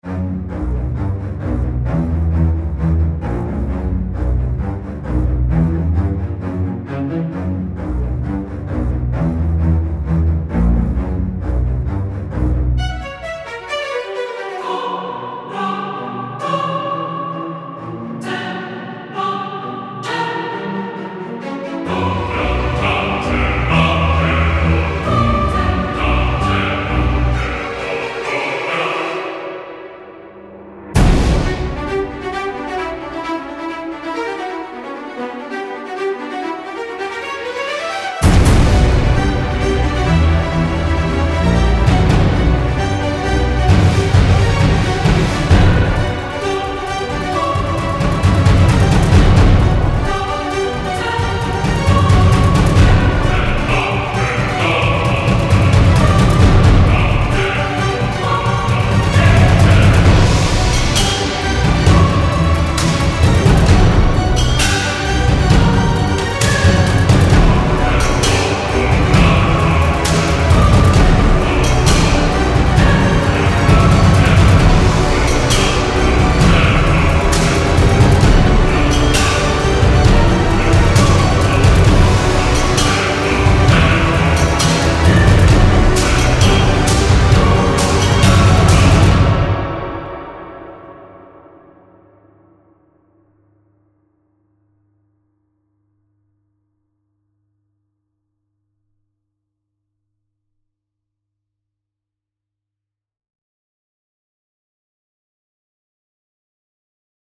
choir1-classical.mp3